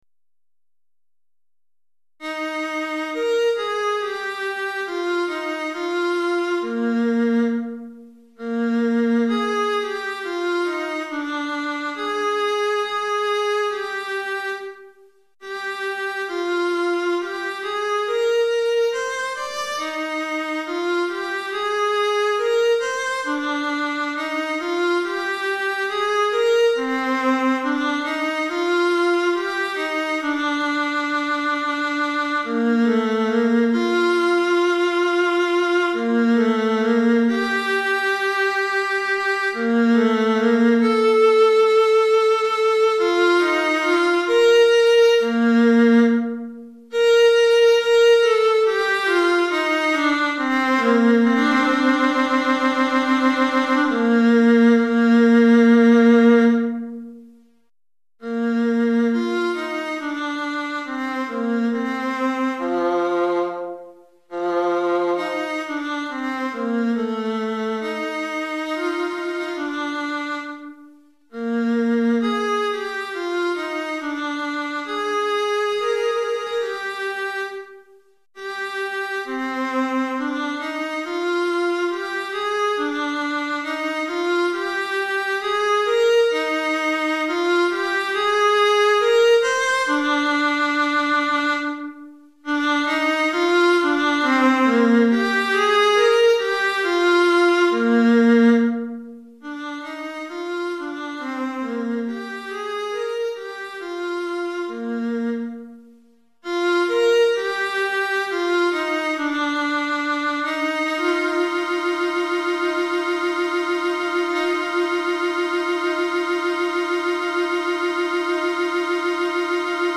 Alto Solo